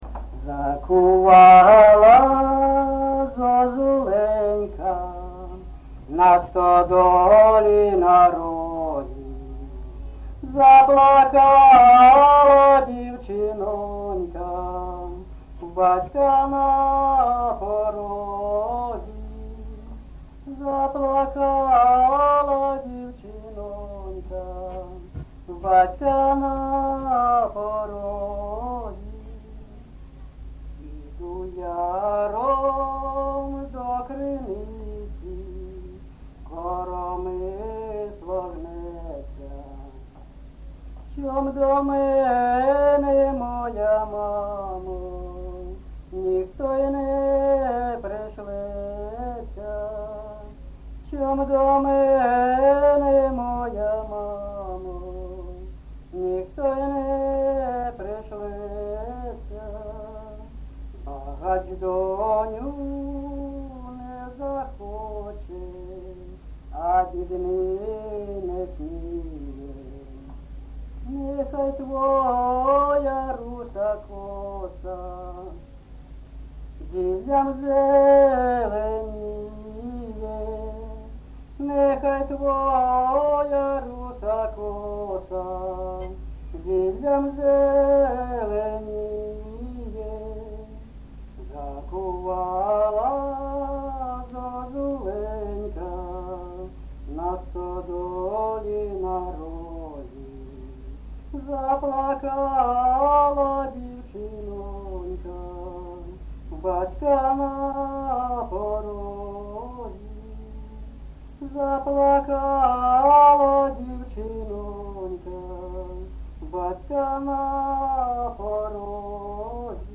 ЖанрПісні з особистого та родинного життя
МотивНещаслива доля, Журба, туга
Місце записус-ще Троїцьке, Сватівський район, Луганська обл., Україна, Слобожанщина